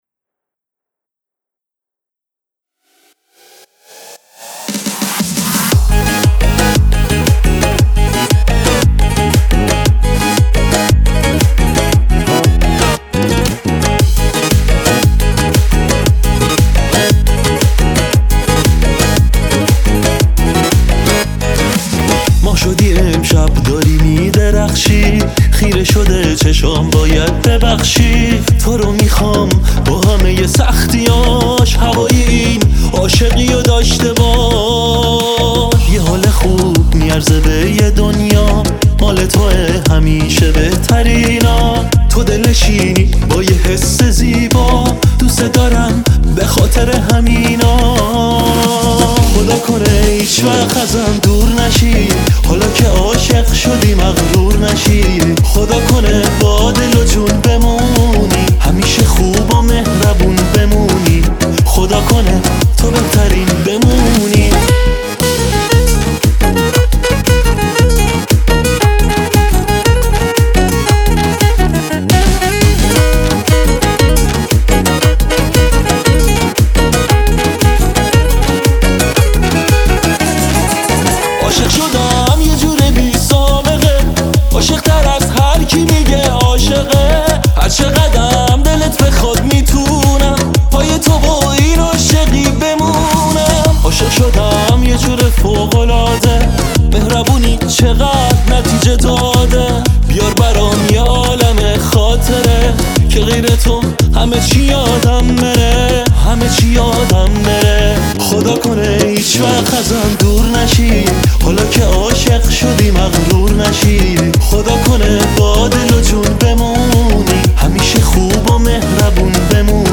آهنگ غمگین